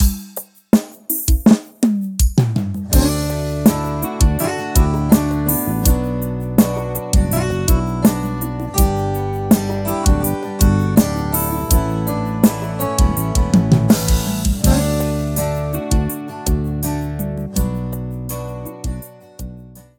• Demonstrativo Pop Sertanejo:
• São todos gravados em Estúdio Profissional, Qualidade 100%